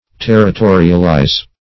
Territorialize \Ter`ri*to"ri*al*ize\, v. t. [imp.